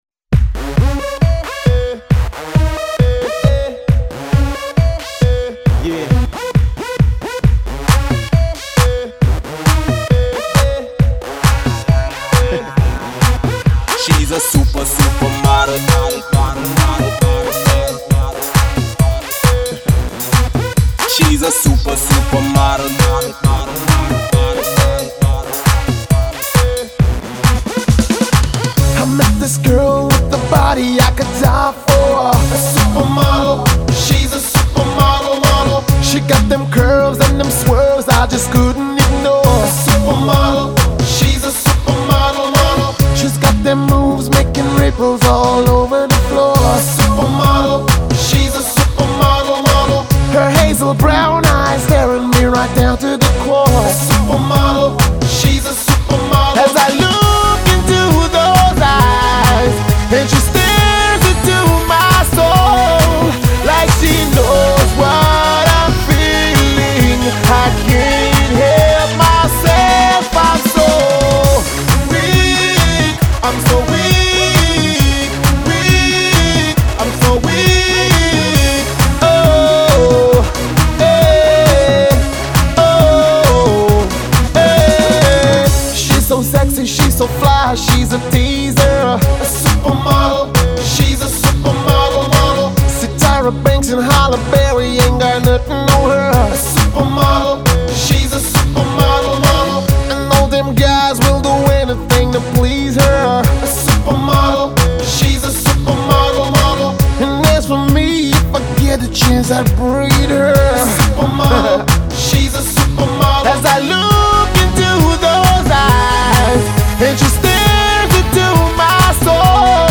Saint Lucian music Media Info Title
Genre: Beat